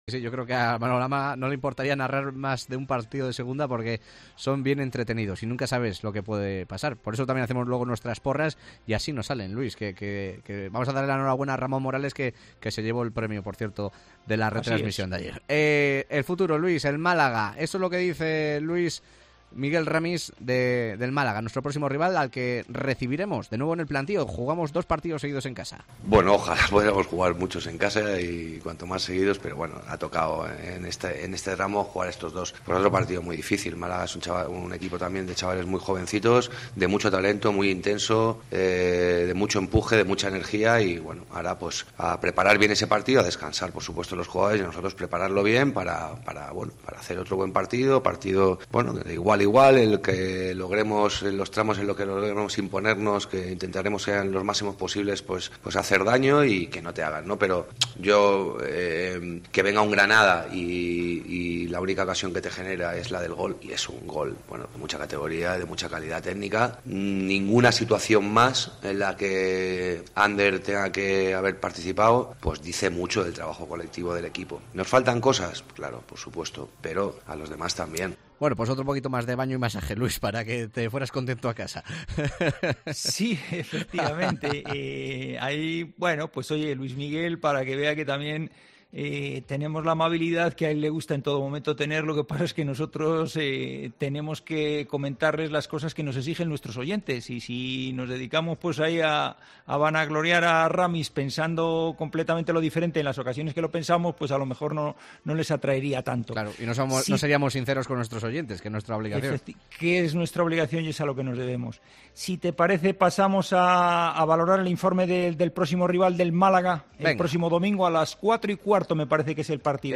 Analista de fútbol y exjugador profesional